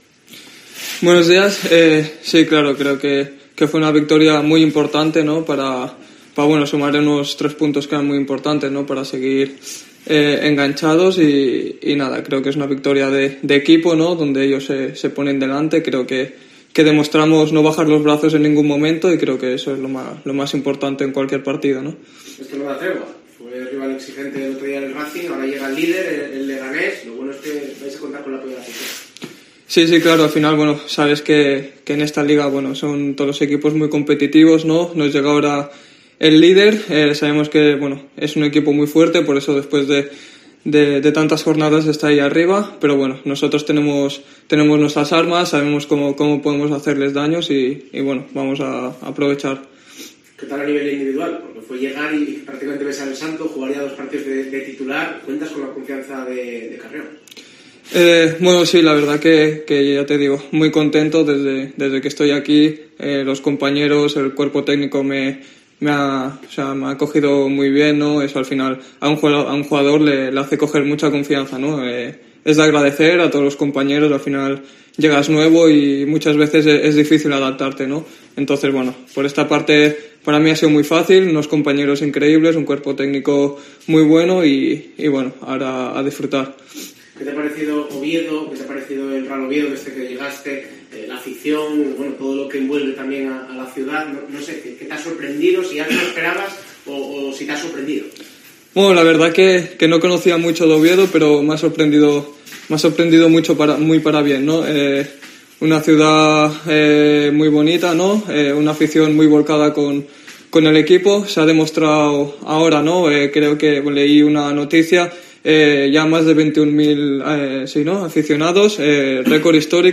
Antes de saltar al verde de El Requexón, Jonathan Dubasin compareció en sala de prensa ante los medios de comunicación.